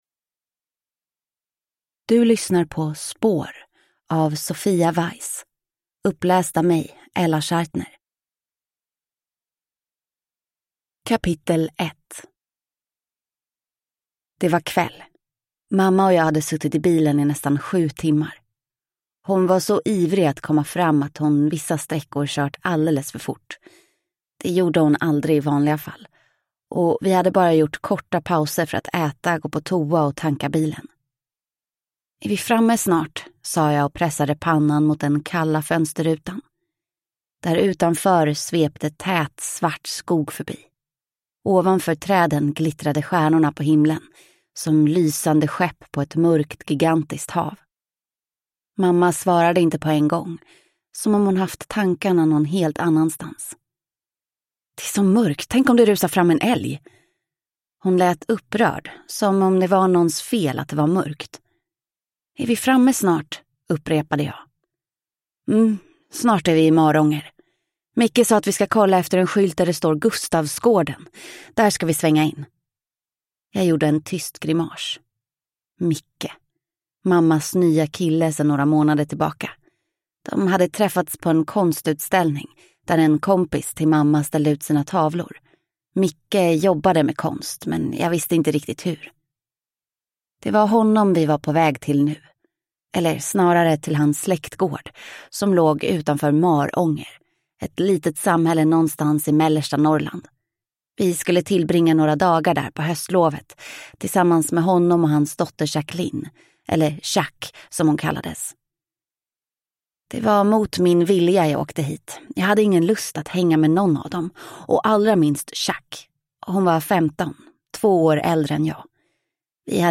Spår (ljudbok) av Sofia Weiss